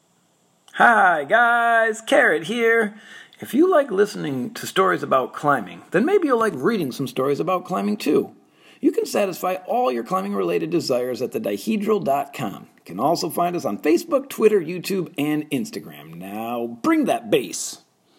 Fabulous sounding voices, all of them.